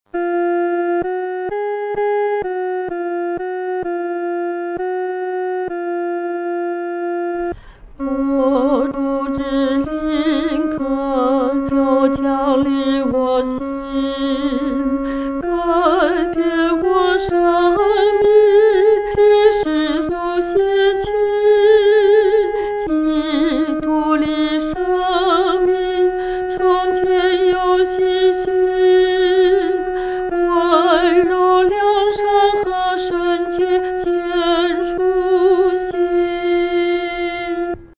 独唱（第二声）
我主之灵恳求降临-独唱（第二声）.mp3